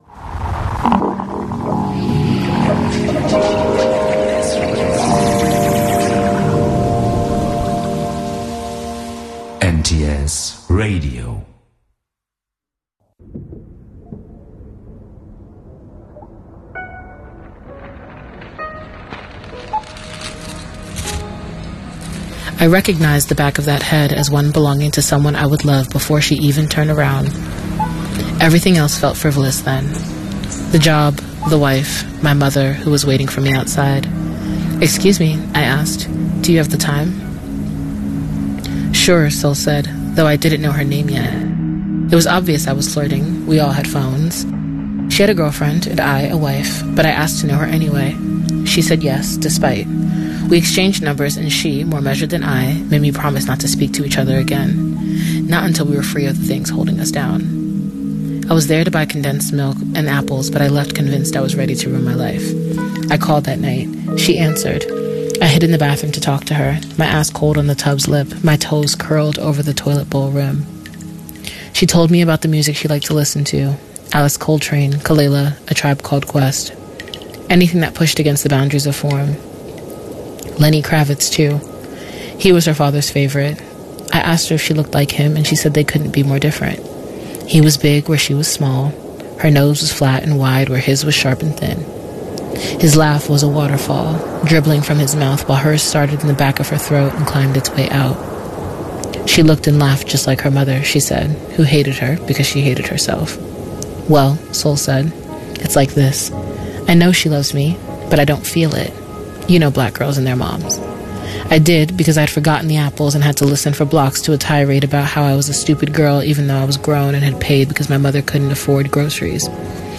A story